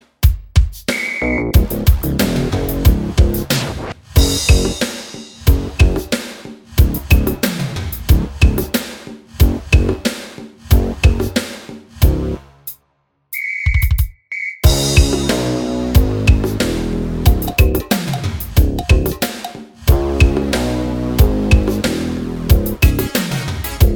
Minus All Guitars Pop (1990s) 3:54 Buy £1.50